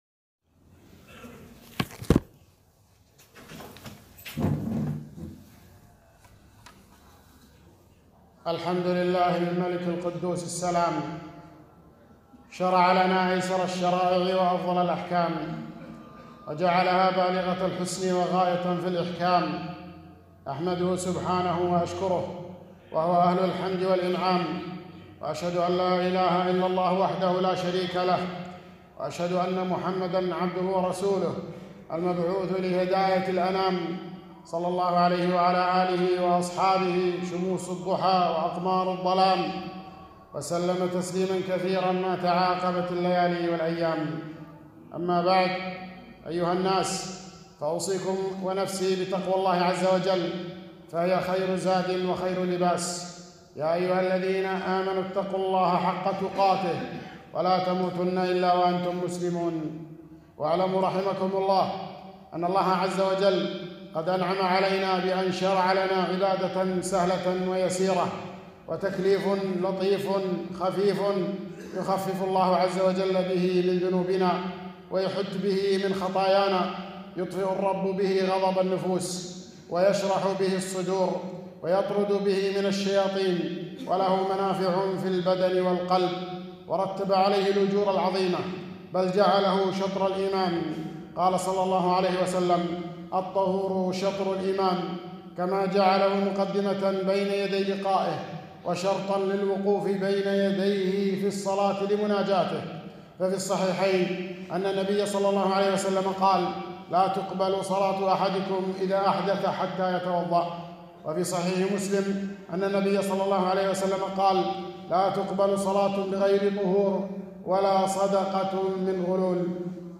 خطبة - فضل الوضوء وأحكامه